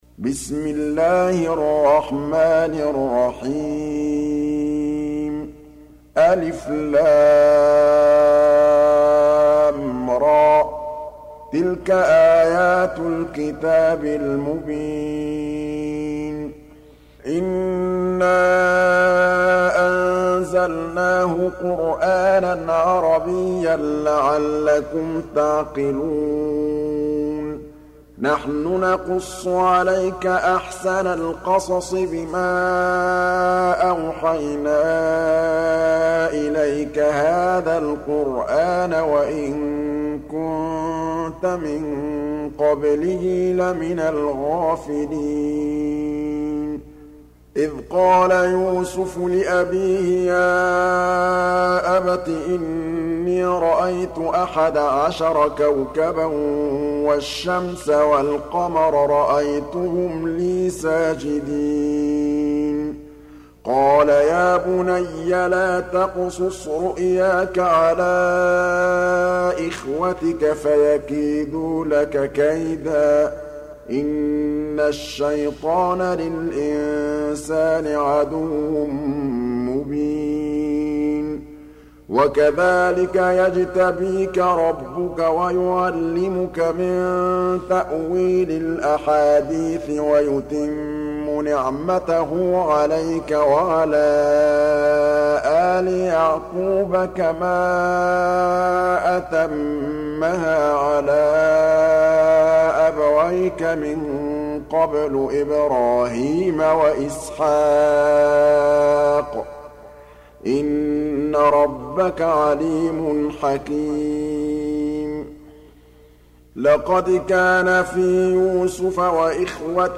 12. Surah Y�suf سورة يوسف Audio Quran Tarteel Recitation
Surah Repeating تكرار السورة Download Surah حمّل السورة Reciting Murattalah Audio for 12.